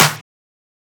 edm-clap-01.wav